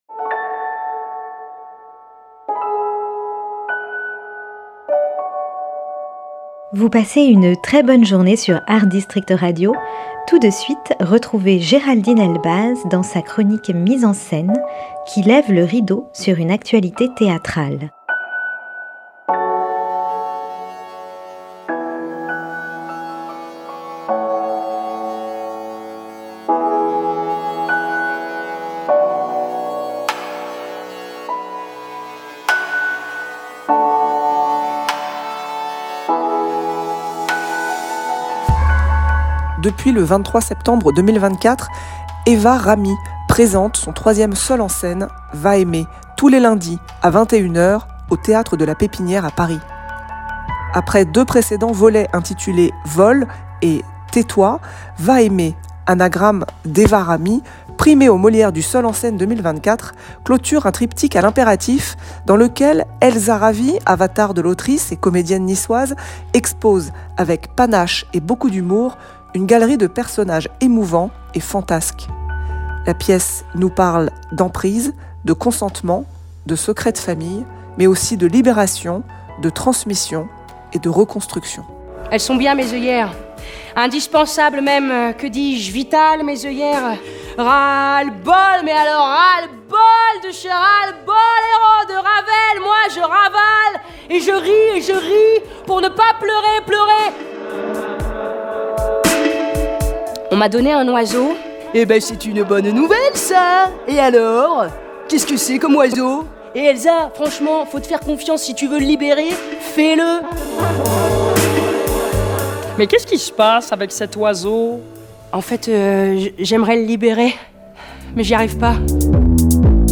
Chronique théâtrale